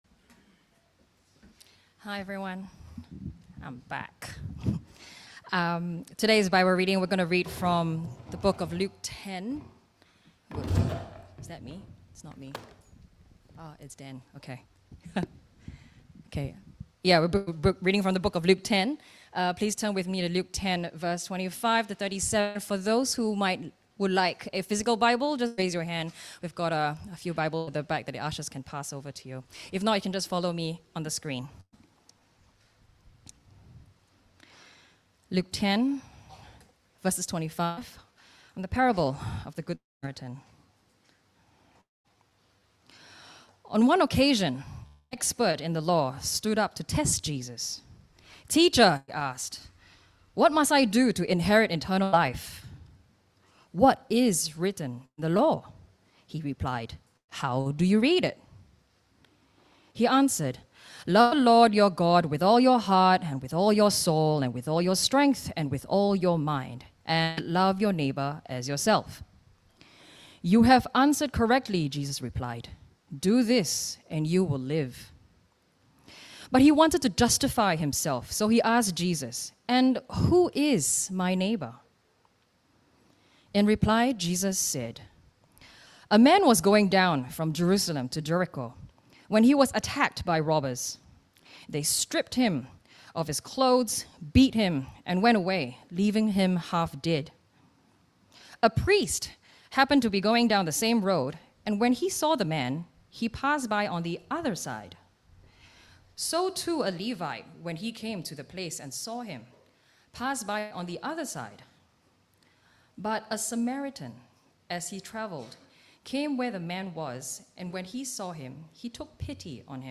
Passage: Luke 10:25-37 Service Type: 10:45 English